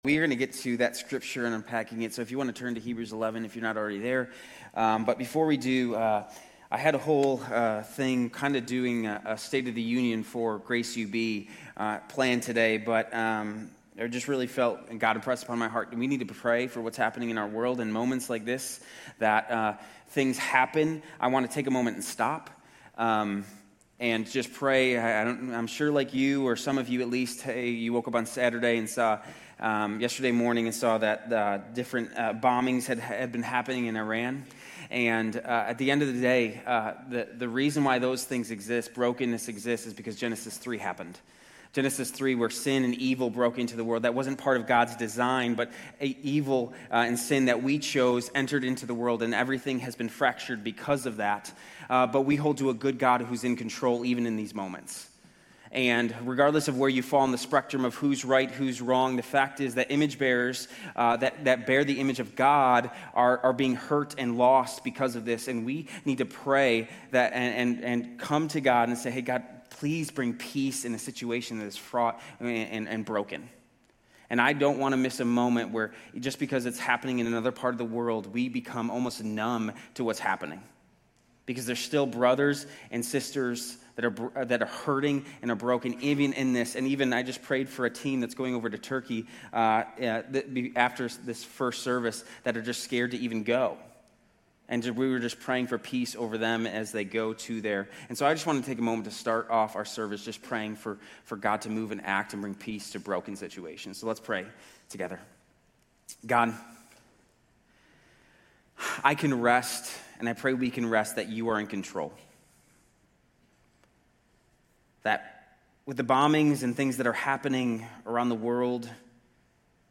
Grace Community Church University Blvd Campus Sermons 3_1 University Blvd.